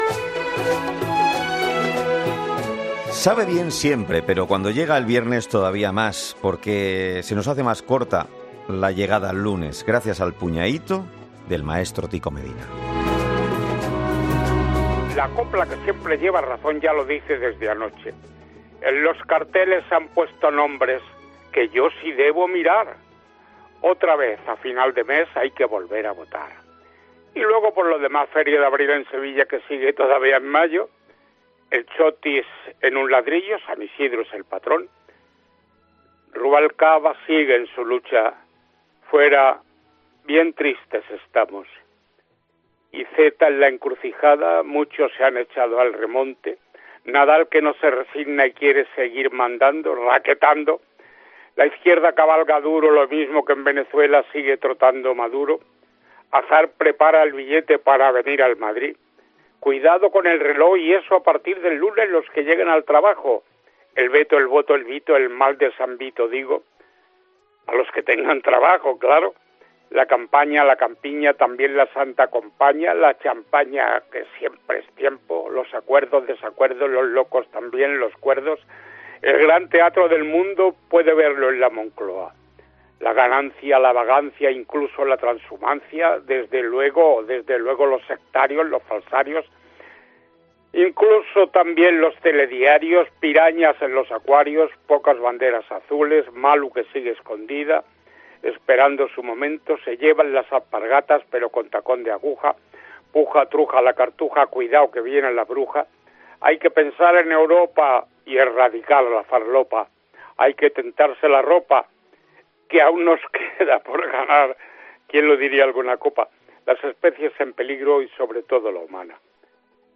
Escucha ahora a Tico Medina, emitido el 10 de mayo del 2019, en 'Herrera en COPE'.